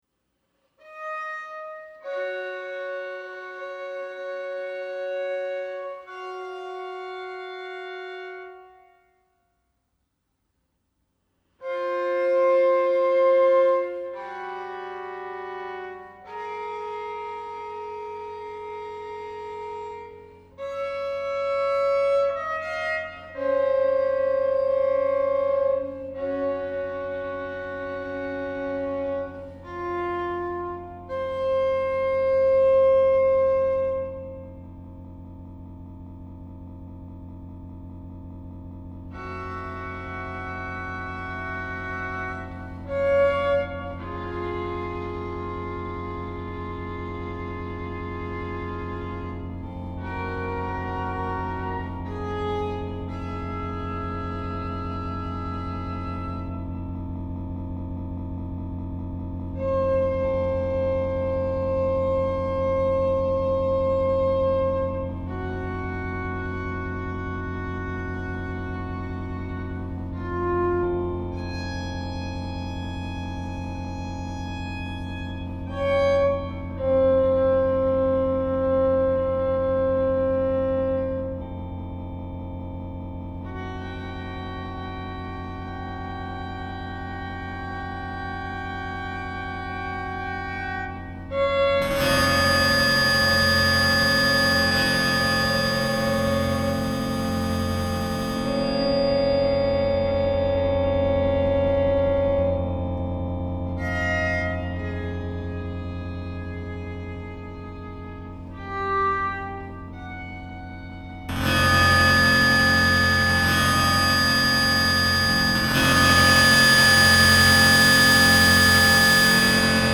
Violin and electronics